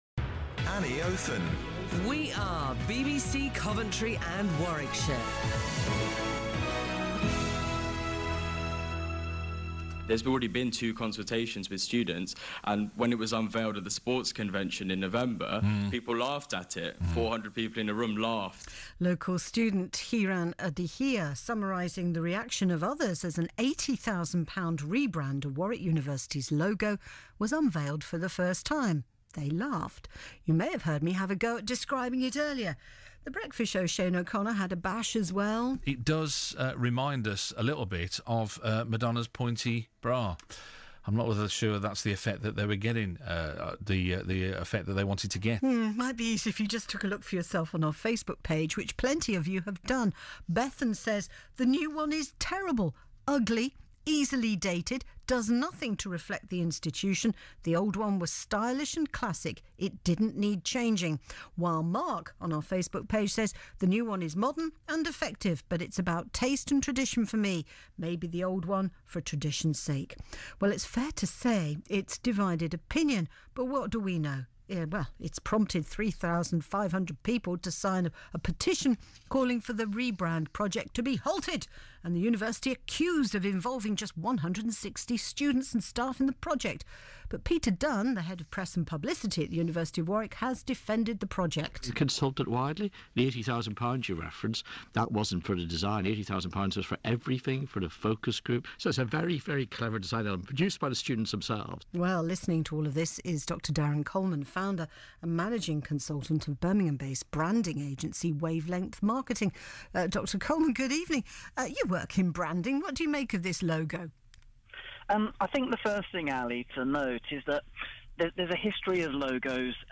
BBC Radio Interview: Wavelength Asked to Comment on University of Warwick’s New Logo